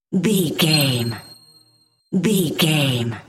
Sound Effects
funny
magical
mystical